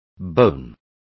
Complete with pronunciation of the translation of bone.